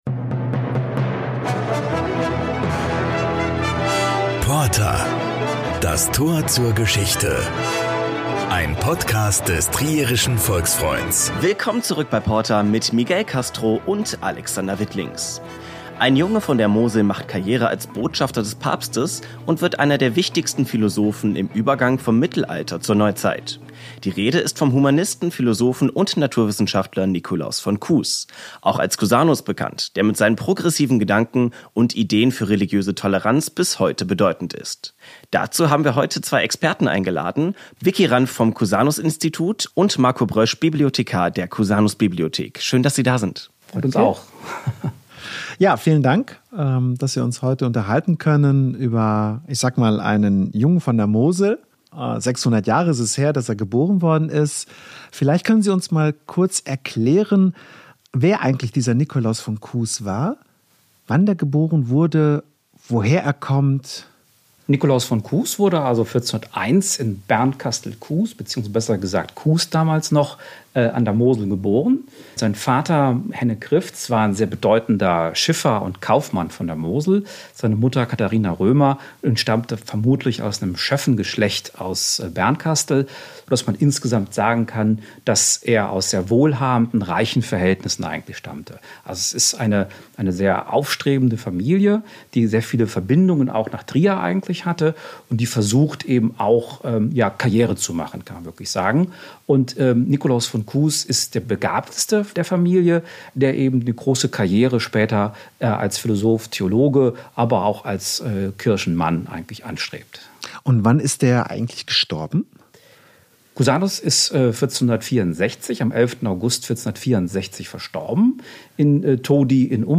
Wer er war und was an ihn heute in Bernkastel-Kues erinnert, erzählen uns zwei Wissenschaftler vom Cusanus-Institut in Trier.